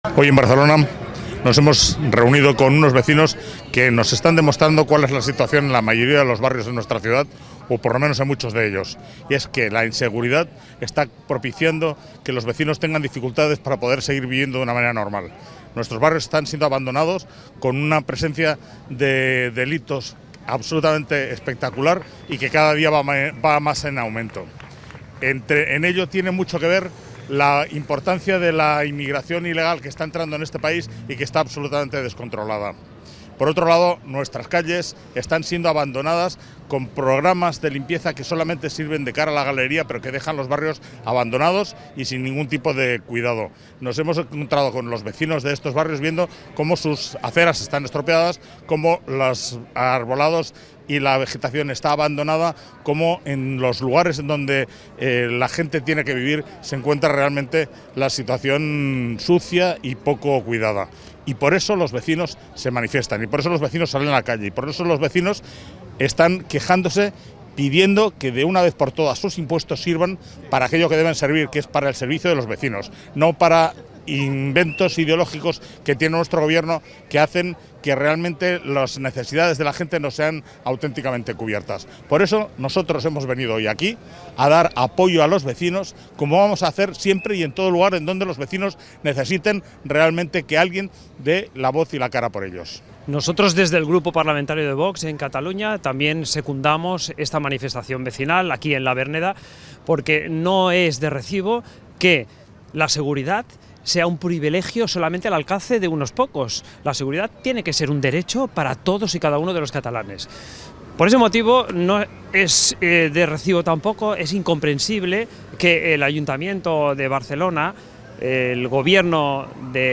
En la tarde-noche del miércoles, varios centenares de vecinos de la zona de la Verneda pertenecientes a una nueva y recién creada plataforma denominada “La Verneda Denuncia” realizaron una manifestación que transcurrió por Rambla Prim con Guipúzcoa hasta Bac de Roda y vuelta.
Manifestaciones Liberto Senderos y Manuel Acosta en La Verneda 15-1-25